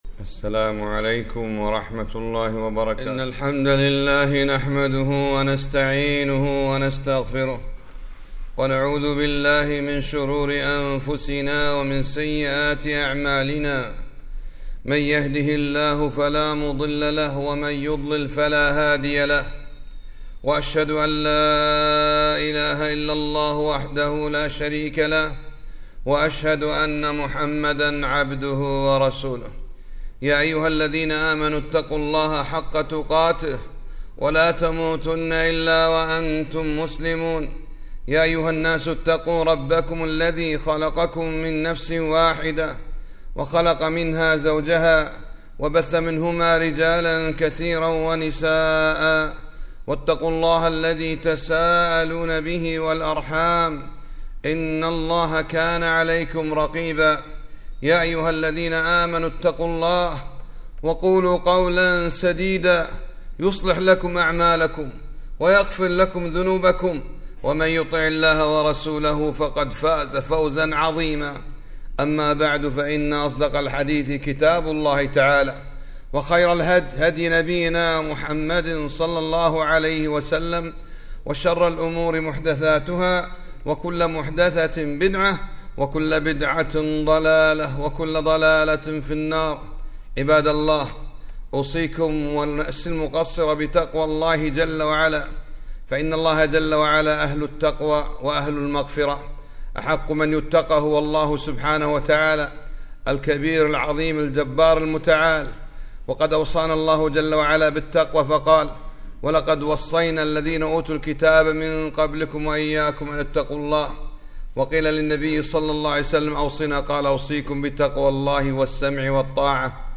خطبة - قال النبي ﷺ ( من جعل الهم هماً واحدة كفاه الله هم دنياه..)